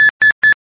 stingerLockOn.ogg